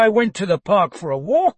tortoise-tts - (A fork of) a multi-voice TTS system trained with an emphasis on quality
scared.mp3